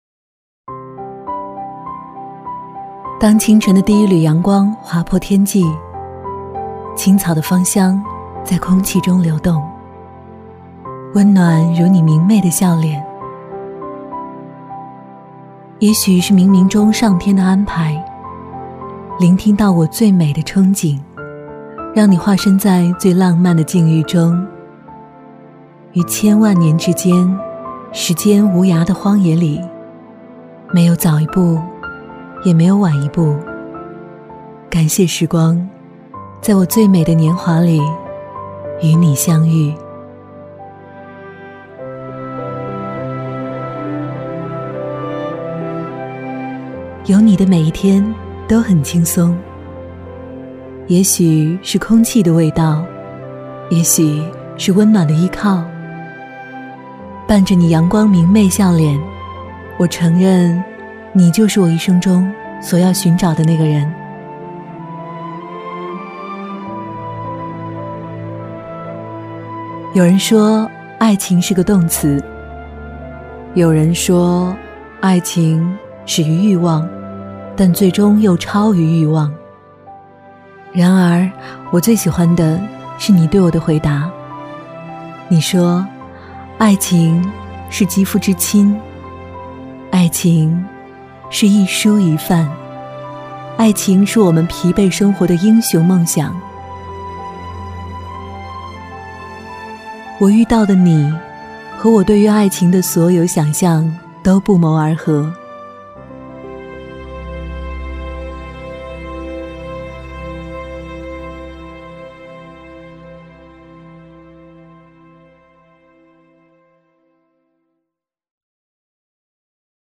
国语青年低沉 、神秘性感 、调性走心 、亲切甜美 、感人煽情 、素人 、女微电影旁白/内心独白 、100元/分钟女S143 国语 女声 微电影旁白-自然之母 -温柔 低沉|神秘性感|调性走心|亲切甜美|感人煽情|素人